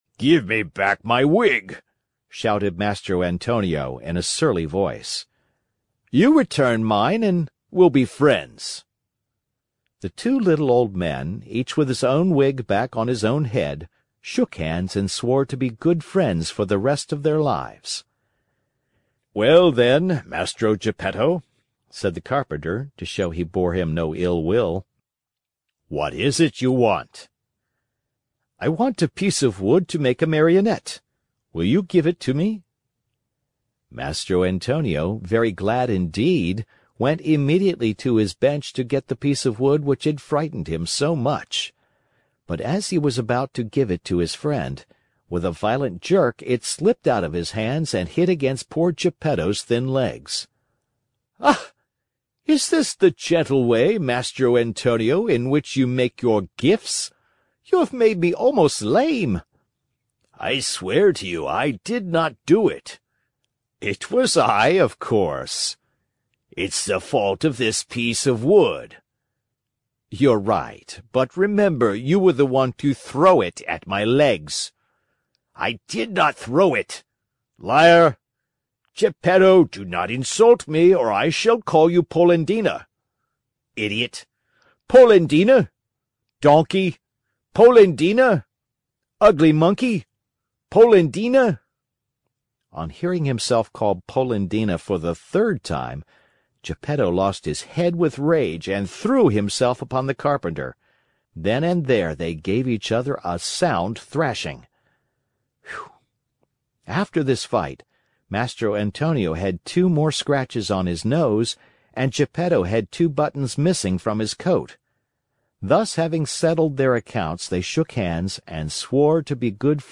在线英语听力室木偶奇遇记 第4期:一辈子做好朋友的听力文件下载,《木偶奇遇记》是双语童话故事的有声读物，包含中英字幕以及英语听力MP3,是听故事学英语的极好素材。